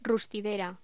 Locución: Rustidera
voz
Sonidos: Voz humana